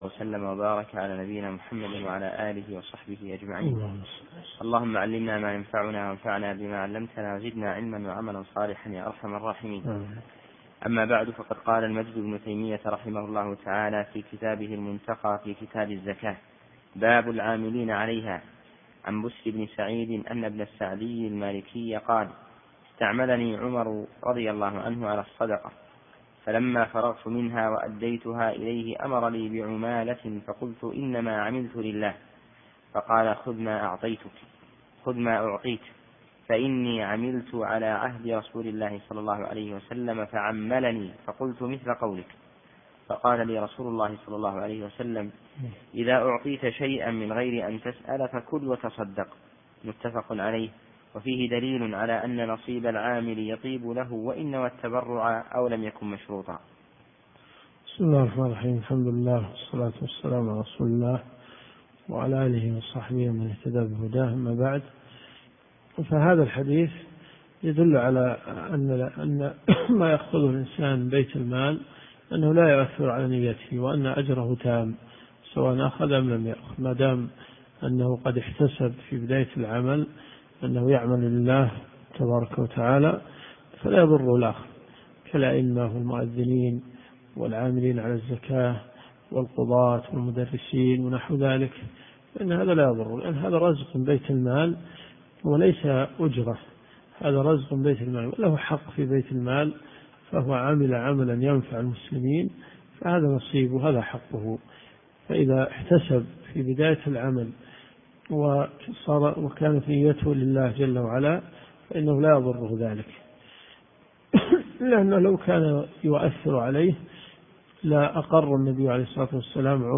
منتقى الاخبار كتاب الزكاة من حديث 2052 إلى حديث 2069 . دورة صيفية في مسجد معاذ بن جبل .